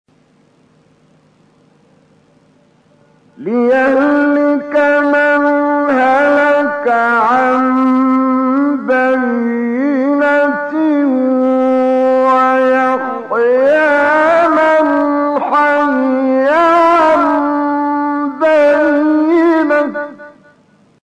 15 فراز از «کامل یوسف» در مقام بیات
گروه شبکه اجتماعی: فرازهای صوتی از کامل یوسف البهتیمی که در مقام بیات اجرا شده است، می‌شنوید.